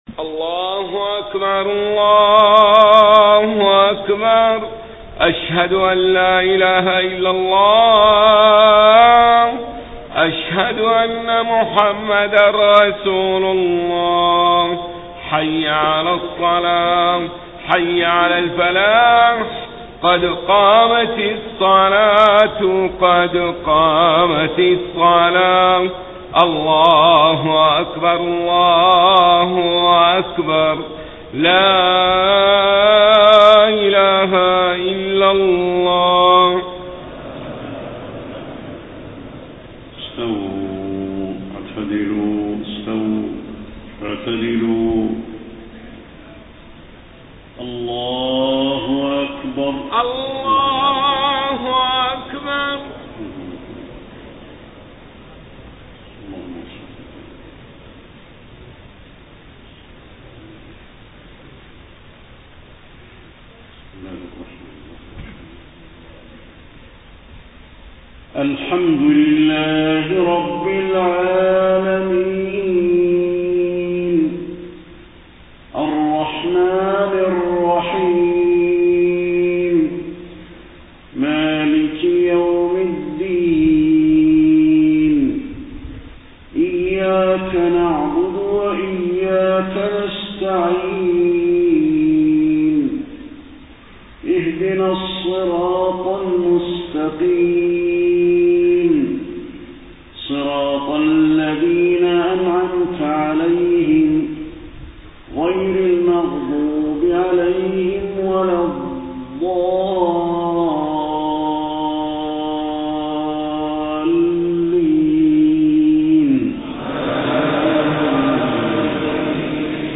صلاة العشاء 30 صفر 1431هـ فواتح سورة الإنسان 1-22 > 1431 🕌 > الفروض - تلاوات الحرمين